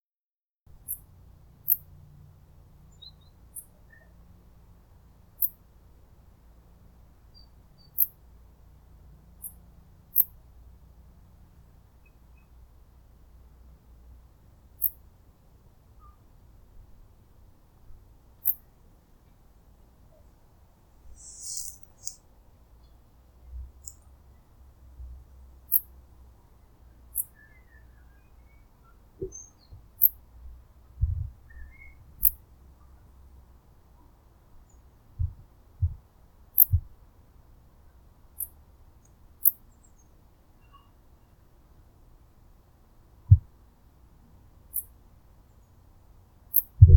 Gesang der Männchen: kurze, schrille Verse, welche aus drei bis vier Silben bestehen.
Feldaudioaufnahme. Dresden, 11.09.2020. Zoom H6, SGH-6 Shotgun Mic Capsule, in der Lautstärke verstärkte Aufnahme und rauschvermindert.
1026_0_P_griseoaptera.wav